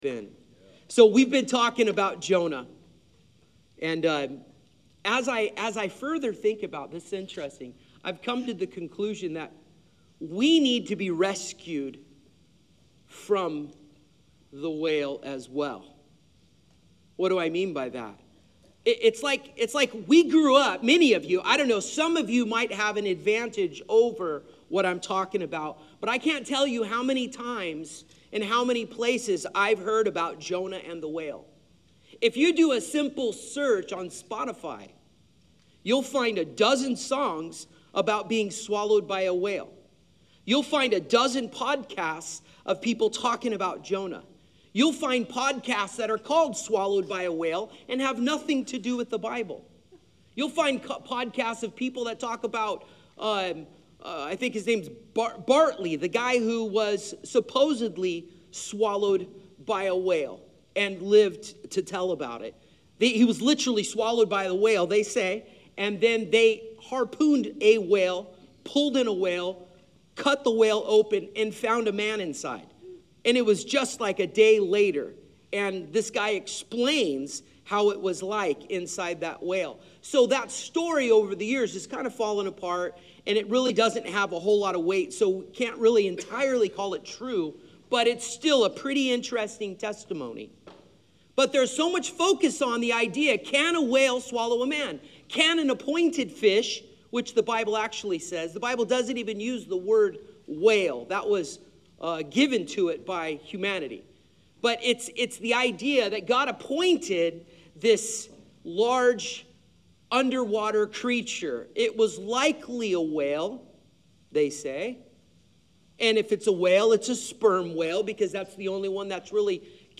Today at LifeHouse Church, we preached on Jonah 3—Reviving!